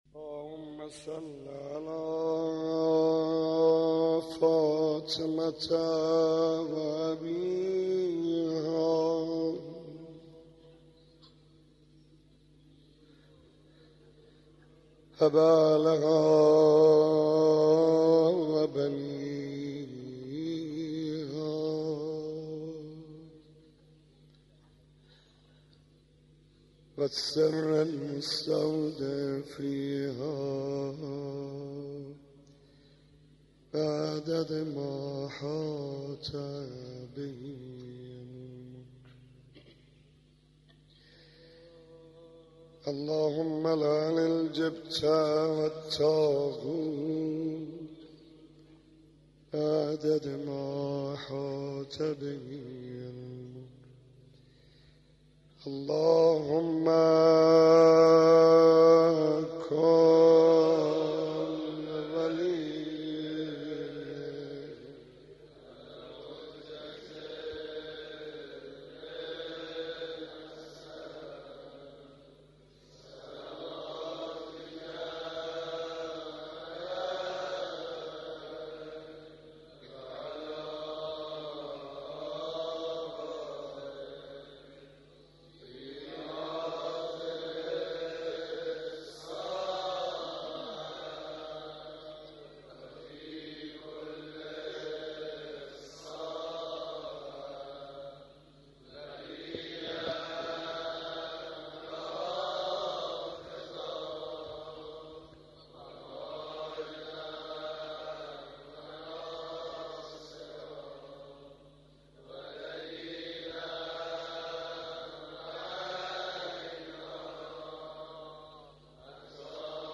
مناسبت : شب چهارم محرم